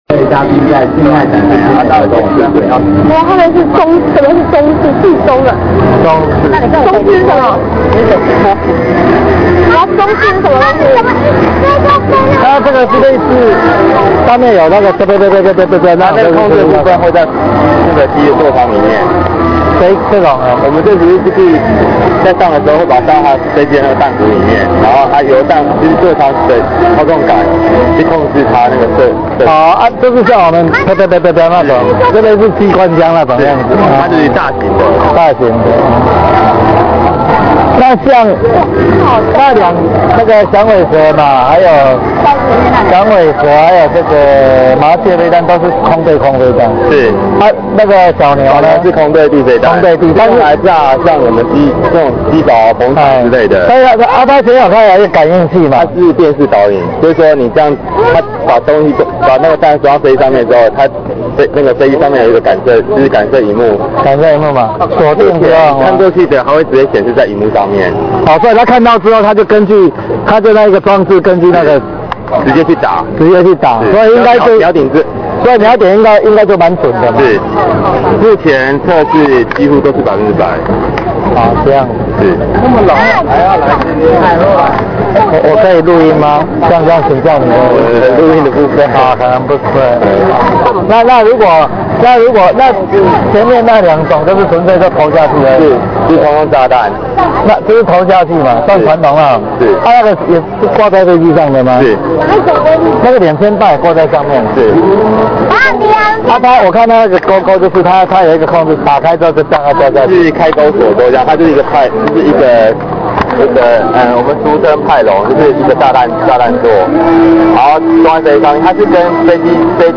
021訪談